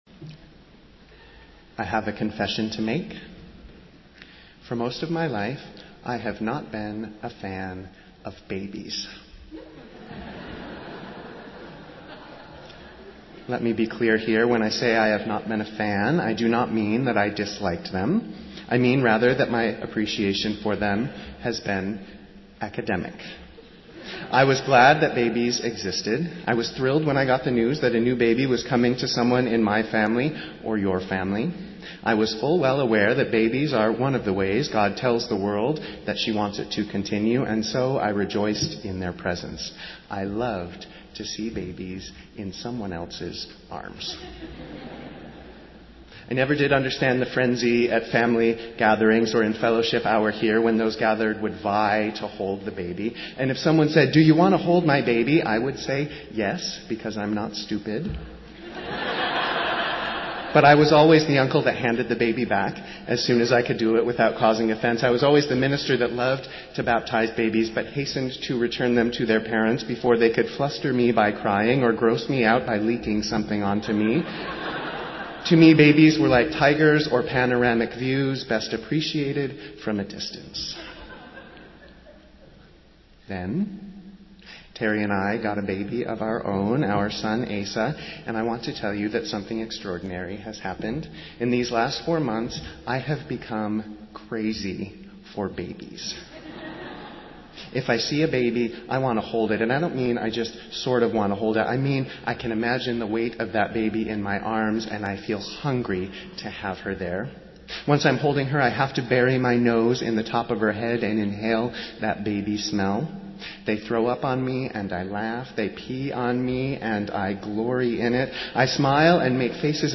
Festival Worship - Baptism of Jesus Sunday